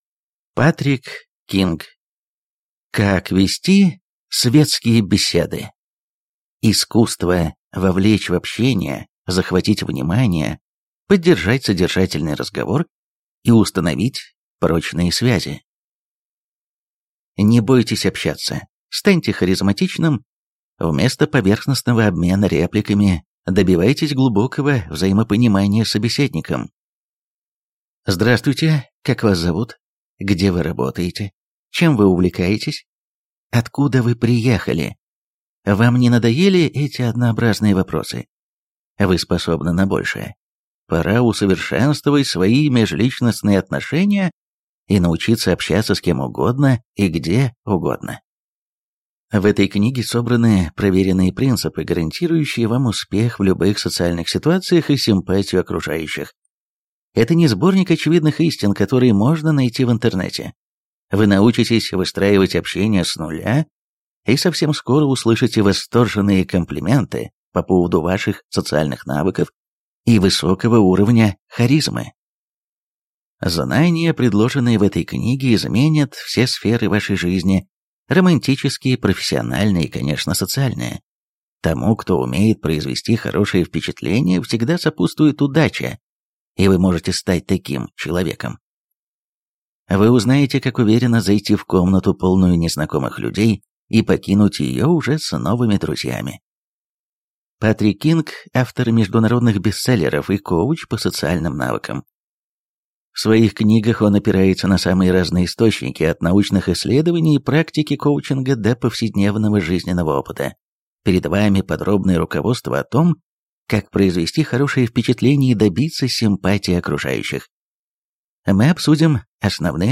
Aудиокнига Как вести светские беседы.